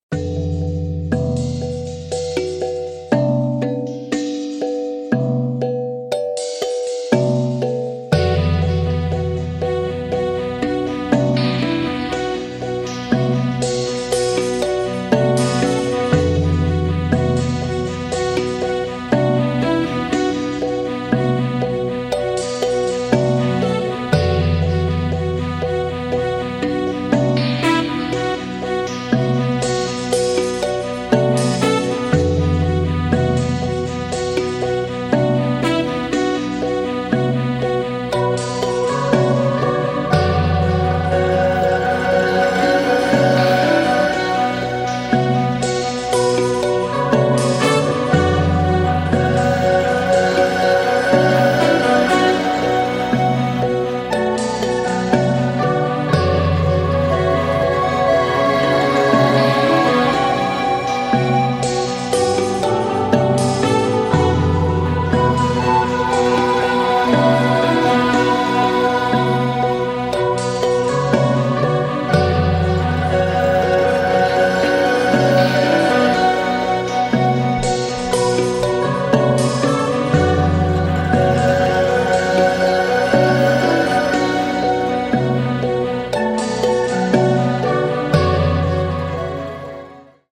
Style:World / Electronic / Other